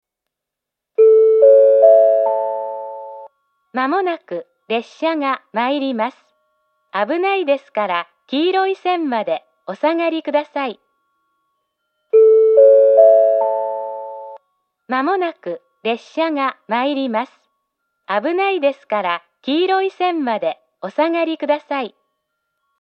１番線接近放送 交換が無い場合上下ともこのホームを使用します。